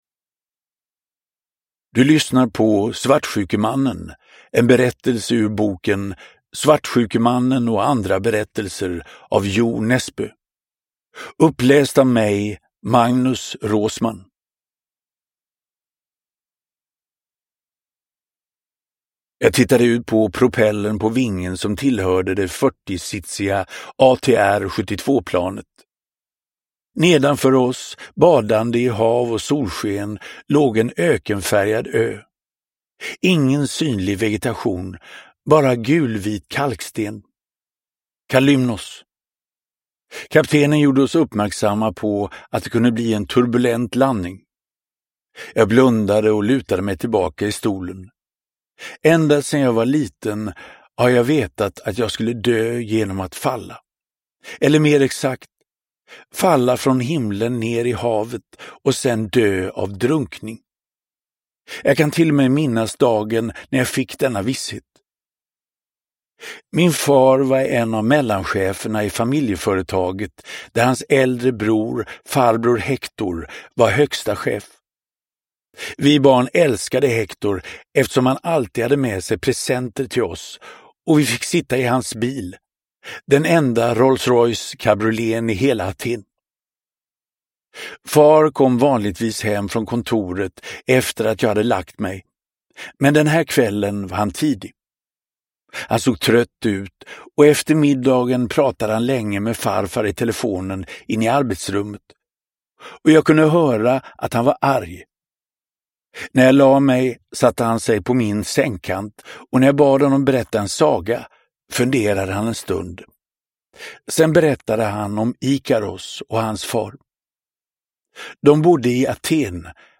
Uppläsare: Magnus Roosmann